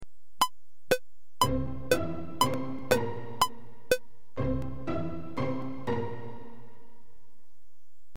Note, these are all the same tempo; 120 BPM (beats per minute).